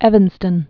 (ĕvən-stən)